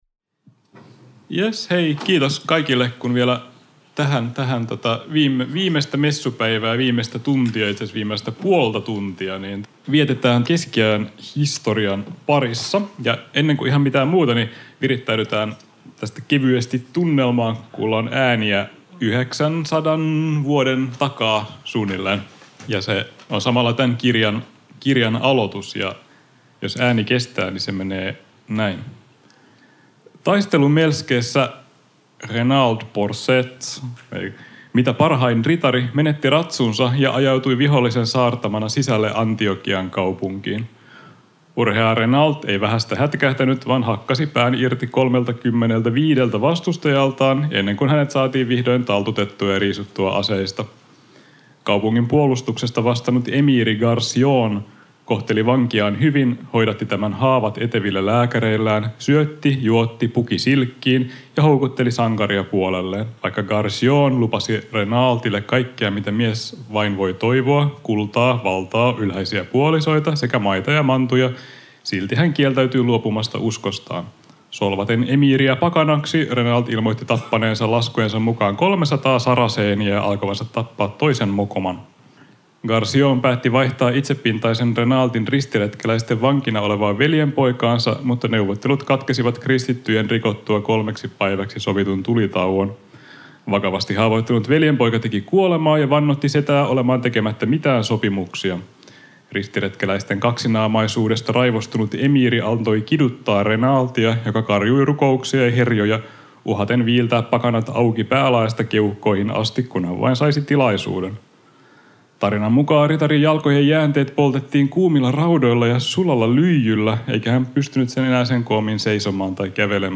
Tampereen Kirjafestareilla